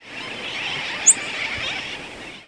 Short rising seeps
Prothonotary Warbler ex1 ex2 ex3